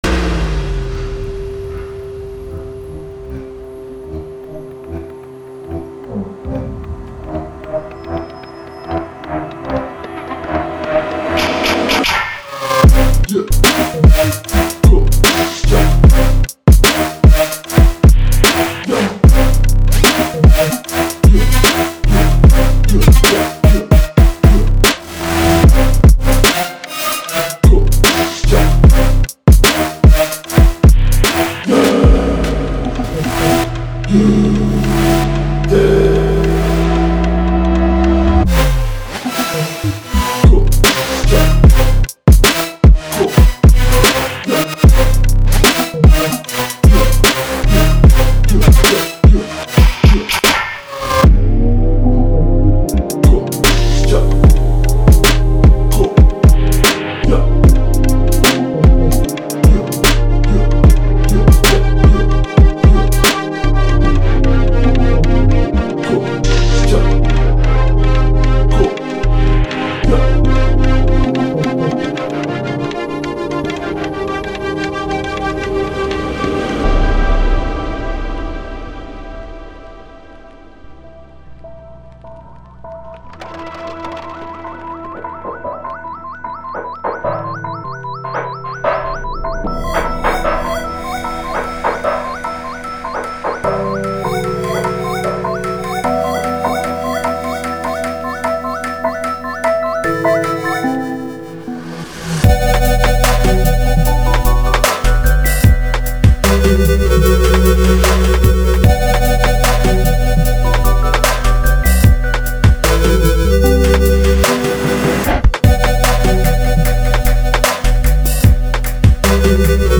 从Trap，Hip Hop，EDM，Techno等多种音乐风格中汲取影响力
这些范围包括实验性冒口，类似打击乐的一击，冗长的撞击，非正统的纹理等等。
喜怒无常，但精力充沛，令人兴奋，但镇定，激进且充满环境感，并且可以使您的脸部变得整洁。“
• 节奏-130，150BPM